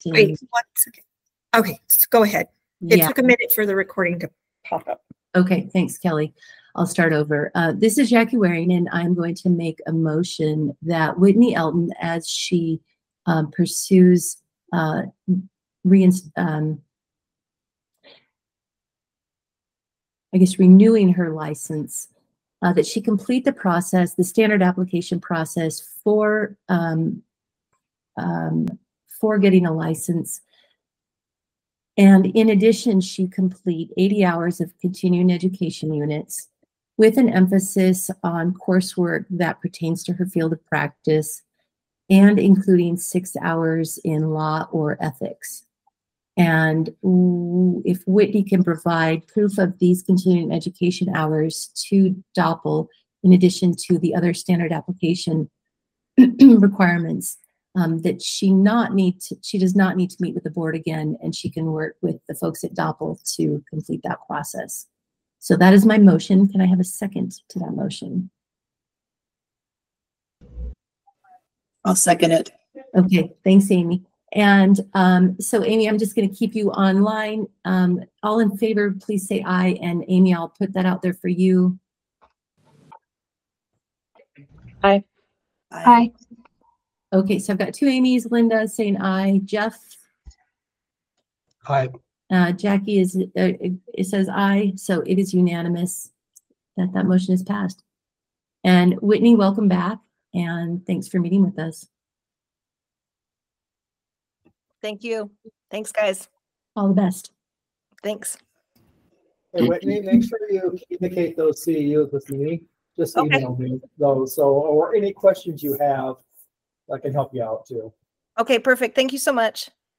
Meeting
Electronic participation is planned for this meeting.